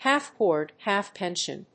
half+board,+half+pension.mp3